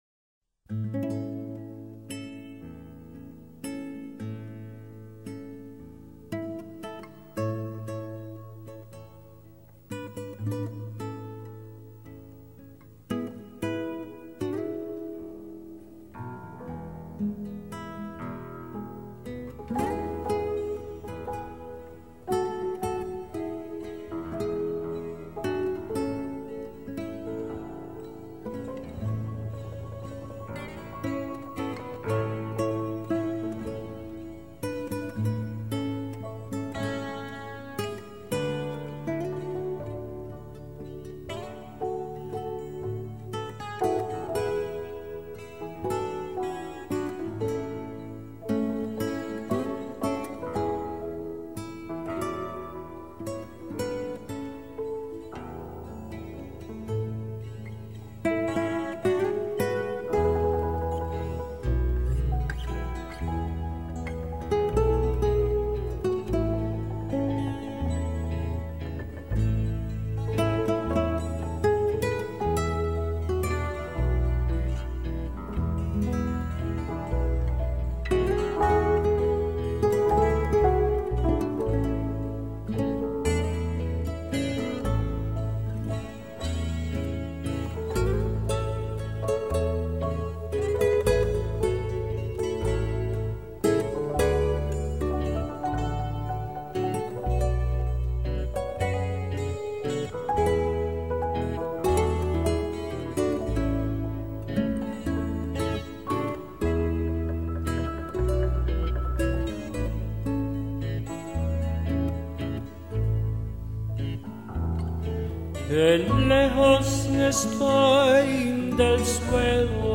类型：原声
音乐与情节，演员的表演以及镜头的节奏配合得丝丝入扣，是一种维美的忧伤。
此前，你无论如何也想象不出这件乐器居然能幻化出如此丰富动人的音色。
HI-FI的每一项指标都得到具体示范。